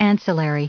added pronounciation and merriam webster audio
206_ancillary.ogg